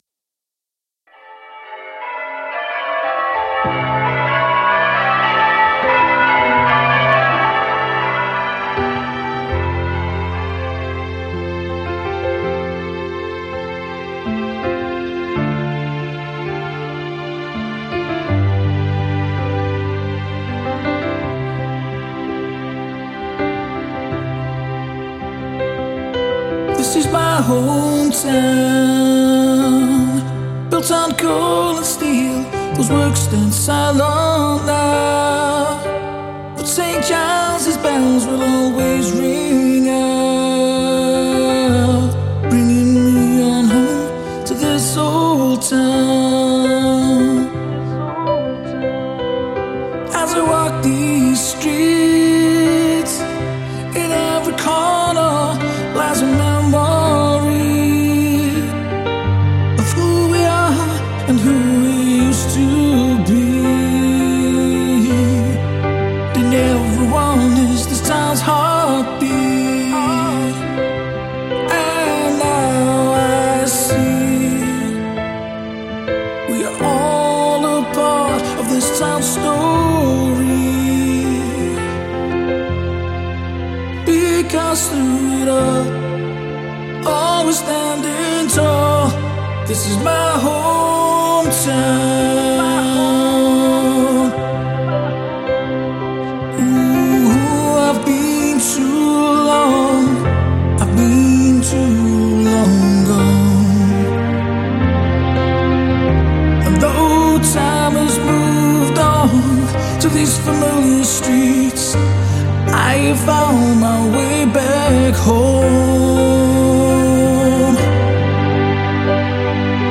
A piano track that started the project.